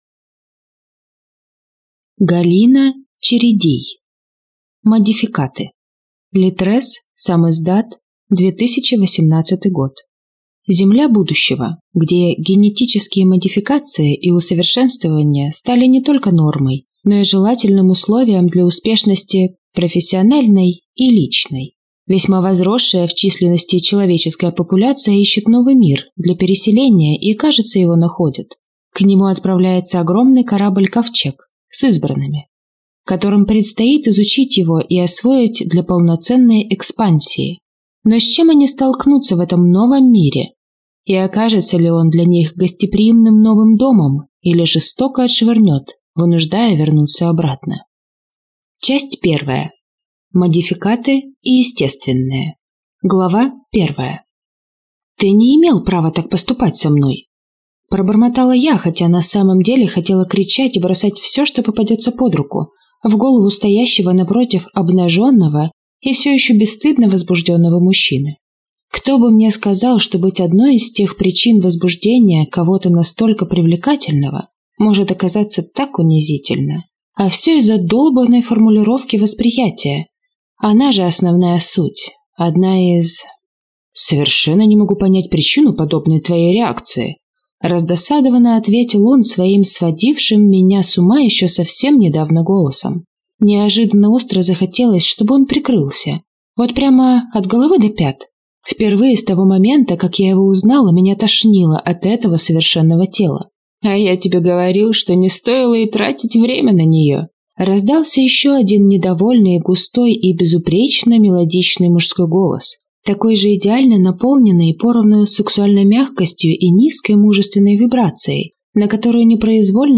Аудиокнига Модификаты | Библиотека аудиокниг